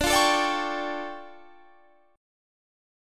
DmM11 Chord
Listen to DmM11 strummed